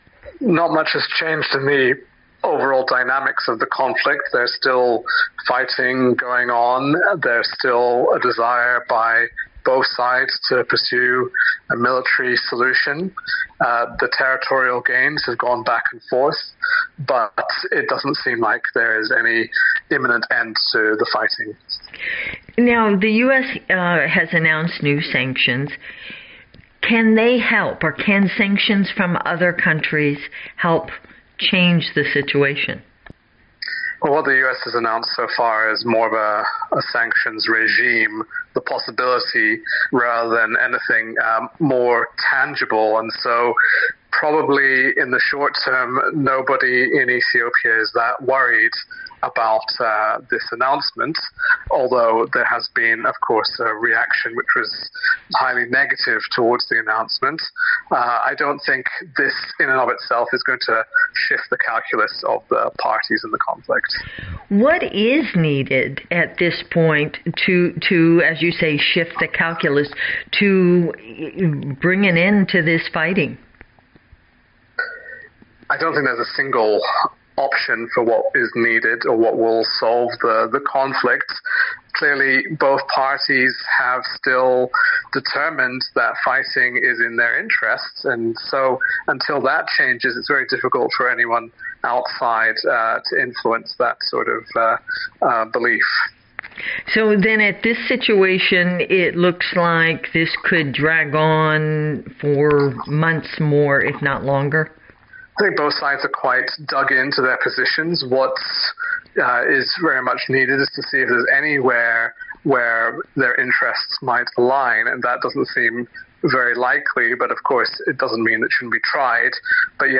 Analyst: US Sanctions Unlikely to Impact Ethiopia Conflict